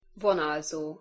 Pronunciation Hu Vonalzó (audio/mpeg)